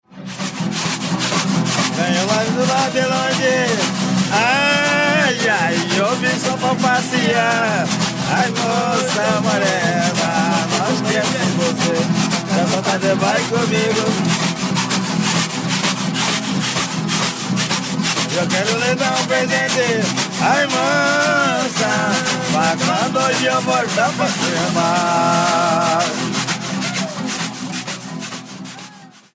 Atividade musical de caráter cerimonial executada por homens que cantam, dançam em roda e tocam violas-de-cocho e ganzás (reco-recos de taquara). Ocorre nas festas de santos no Mato Grosso e Mato Grosso do Sul